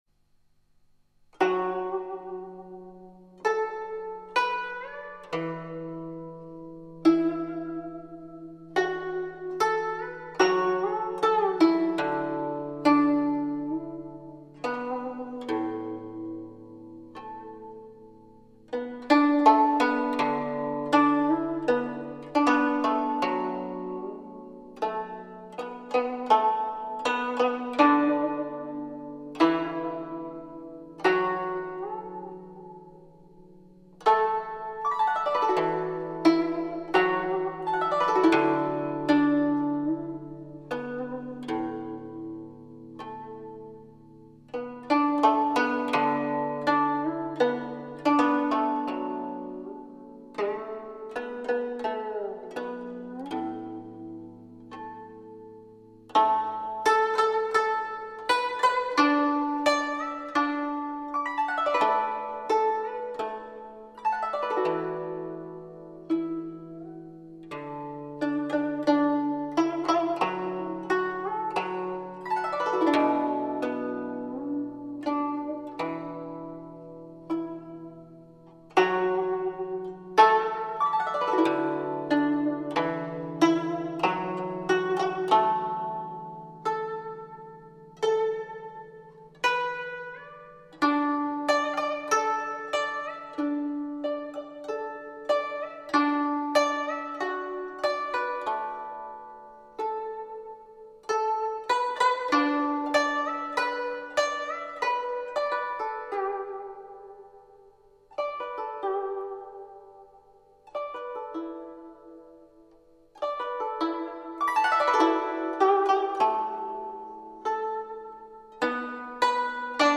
优美的古筝..